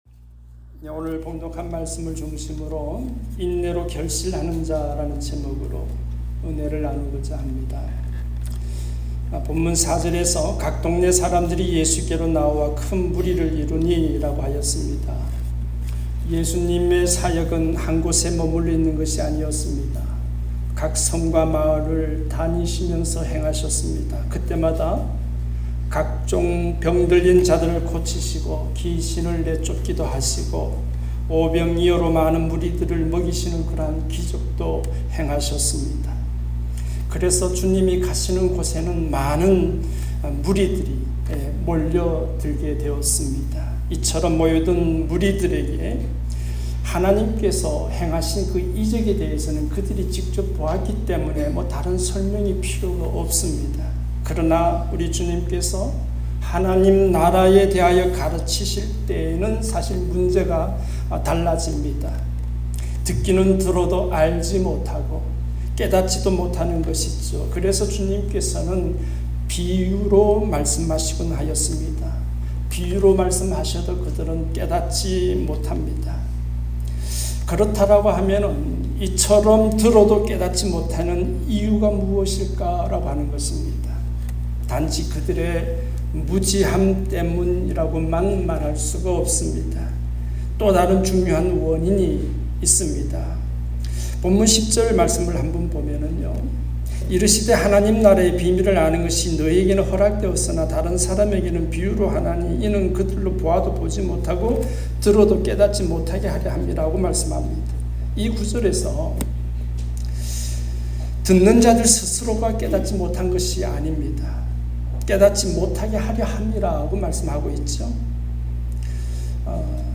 인내로 결실하는 자 ( 눅8:4-15 ) 말씀